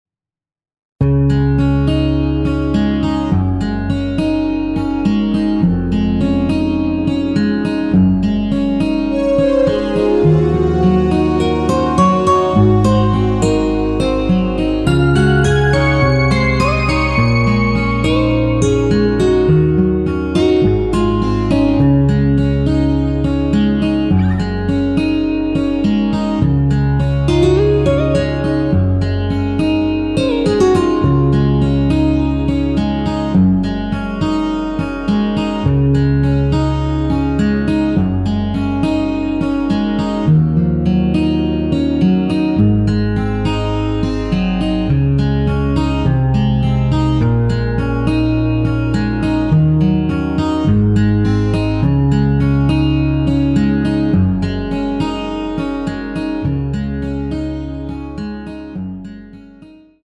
Db
앞부분30초, 뒷부분30초씩 편집해서 올려 드리고 있습니다.
중간에 음이 끈어지고 다시 나오는 이유는
곡명 옆 (-1)은 반음 내림, (+1)은 반음 올림 입니다.